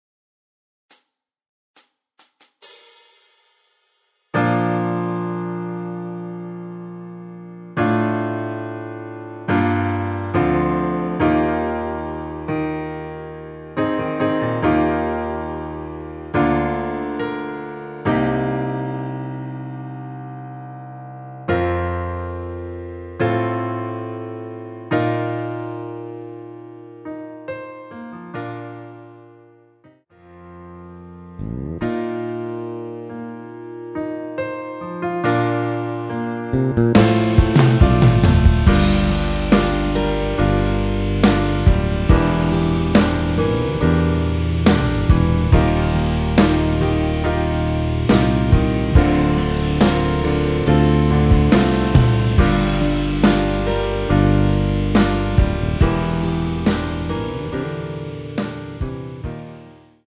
원키에서(+6)올린 MR입니다.
전주없이 노래가 시작되는 곡이라 카운트 만들어 놓았습니다.
Bb
앞부분30초, 뒷부분30초씩 편집해서 올려 드리고 있습니다.
중간에 음이 끈어지고 다시 나오는 이유는